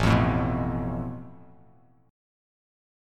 Asus2#5 chord